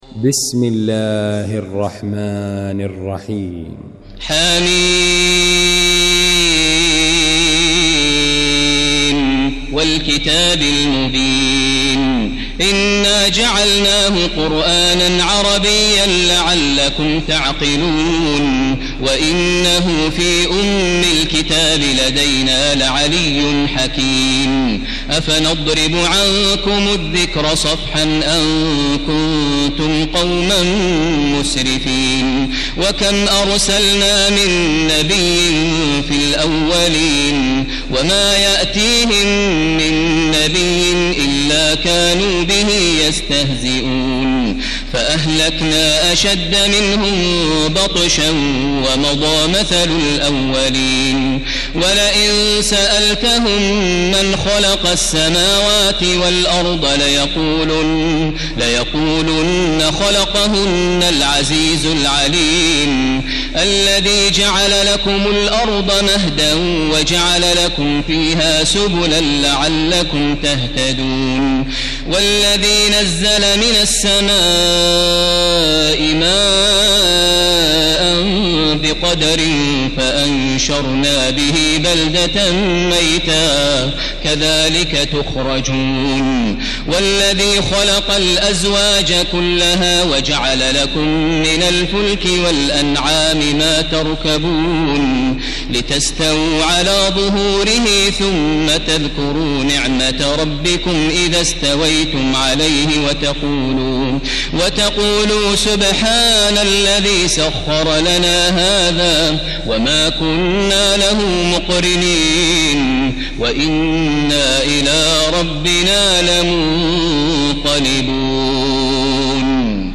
المكان: المسجد الحرام الشيخ: فضيلة الشيخ ماهر المعيقلي فضيلة الشيخ ماهر المعيقلي الزخرف